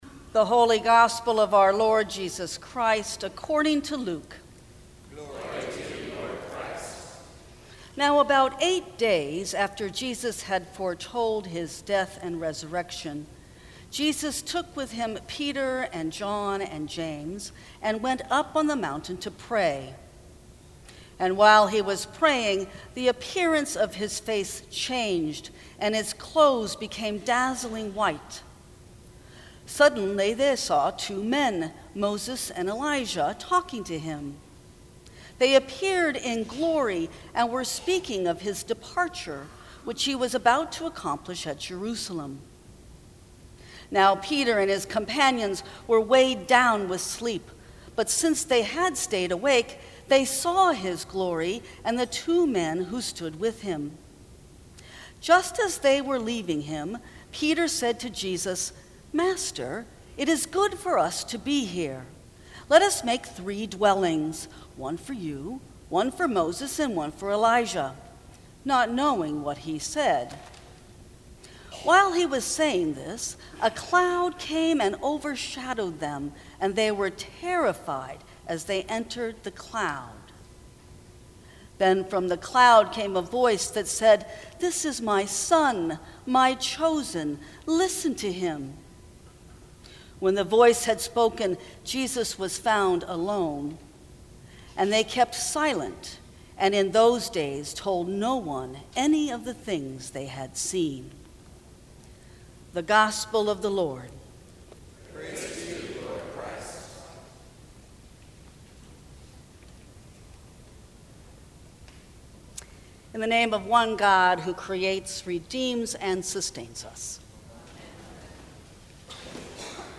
Sermons from St. Cross Episcopal Church Hear Jesus Mar 04 2019 | 00:12:15 1x 00:00 / 00:12:15 Subscribe Share Apple Podcasts Spotify Overcast RSS Feed Share Link Embed